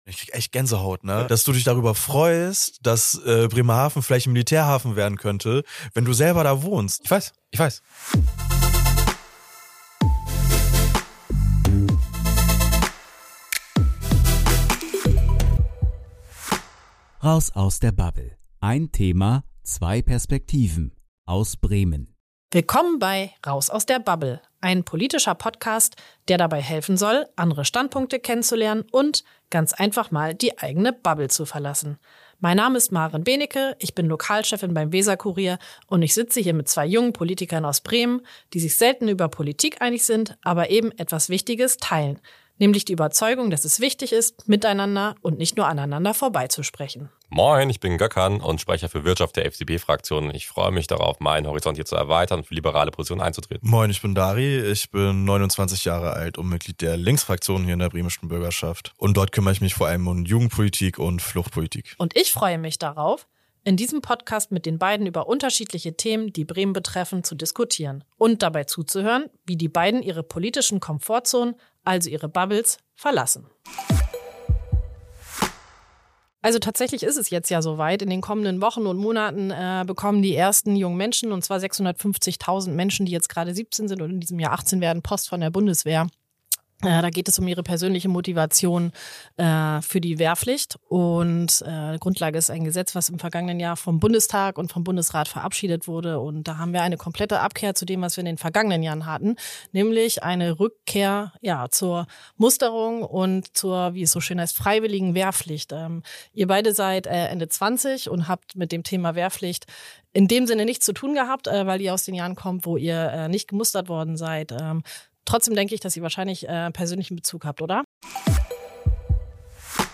In der ersten Folge von „Raus aus der Bubble“ diskutieren die beiden Bremer Politiker